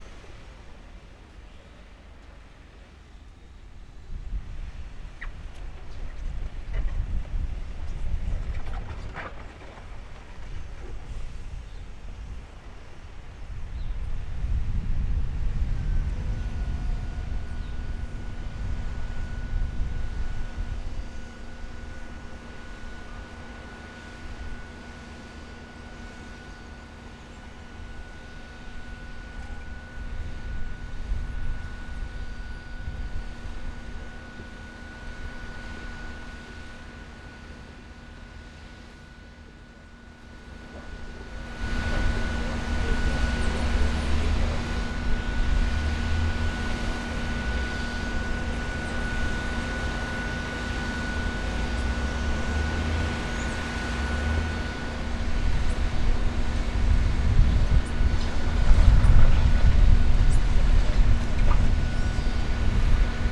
Por ello les invitamos a que nos envíen los sonidos más representativos de su comunidad.
Muelle JAPDEVA LIMÓN